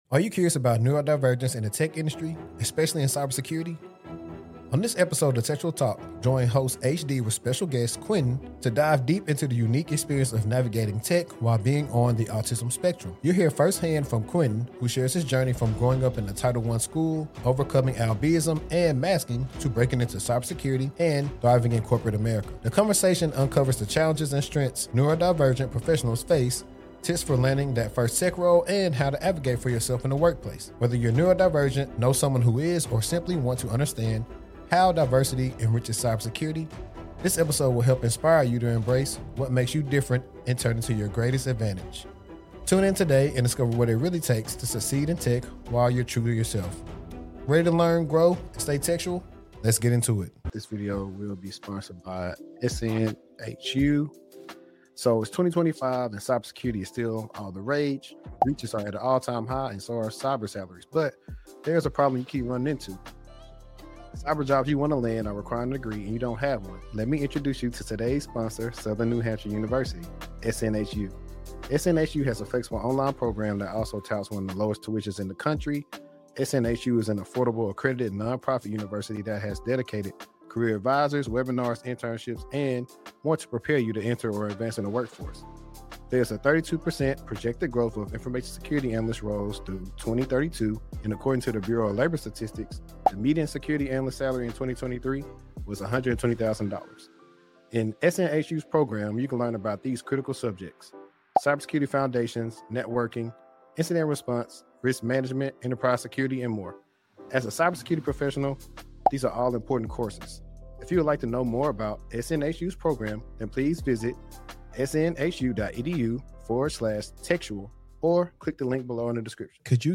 The conversation blends career insights, personal stories, and tips for breaking into tech while neurodivergent.